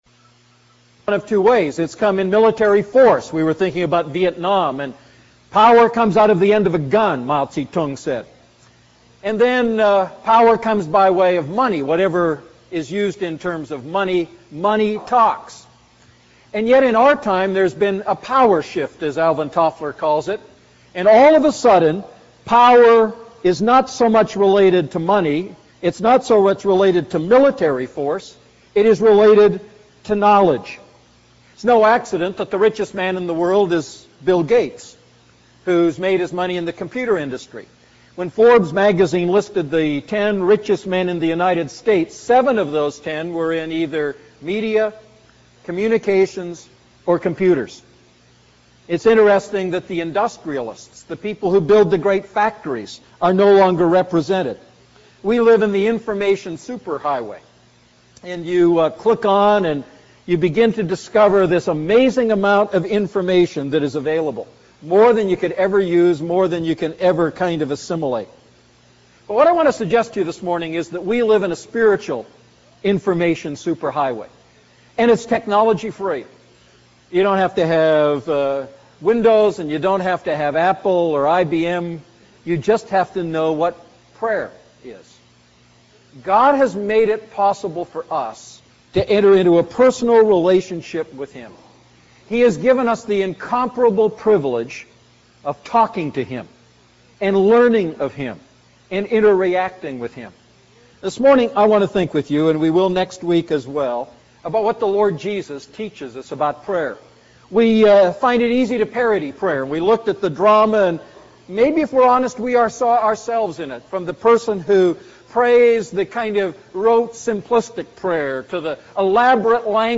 A message from the series "Luke Series II."